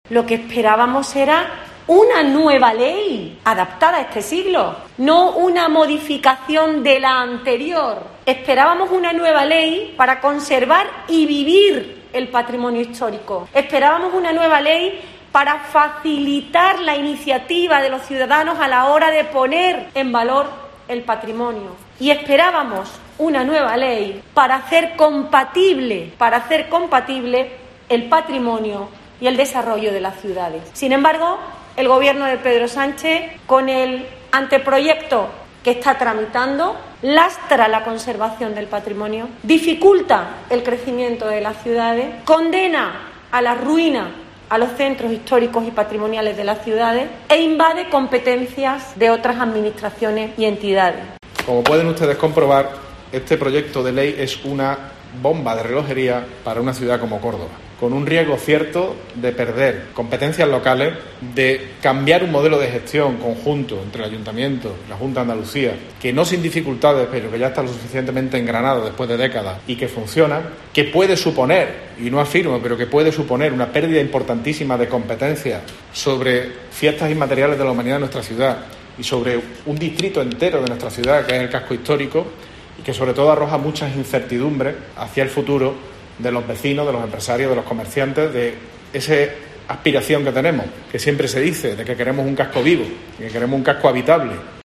Así lo han anunciado, en una rueda de prensa conjunta en el Ayuntamiento cordobés la consejera de Cultura y Patrimonio Histórico, Patricia del Pozo, y el alcalde de la ciudad, José María Bellido, asegurando la consejera que "el Gobierno de Pedro Sánchez, con el anteproyecto que está tramitando lastra la conservación del patrimonio, dificulta el crecimiento de las ciudades, condena a la ruina a los centros históricos y patrimoniales de las ciudades e invade competencias de otras administraciones y entidades", como la Iglesia Católica.